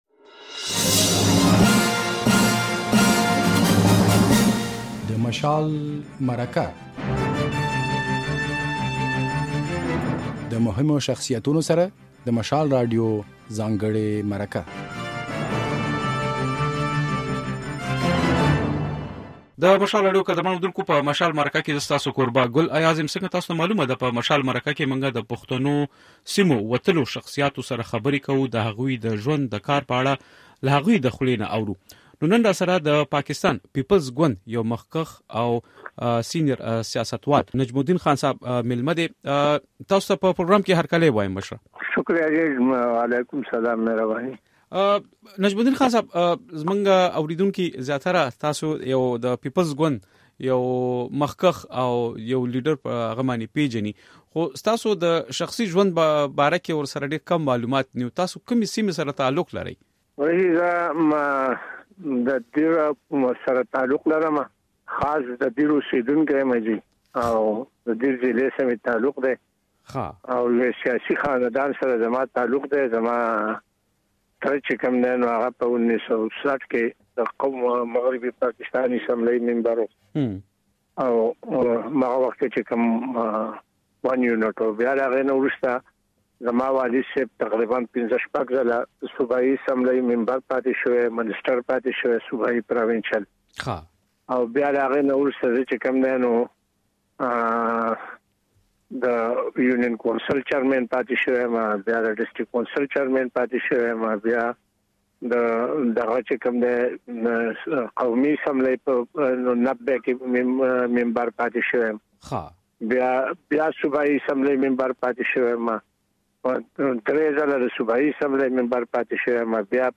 په مشال مرکه کې د پيپلز ګوند د خيبر پښتونخوا صوبايي مشر نجم الدين خان ميلمه دی.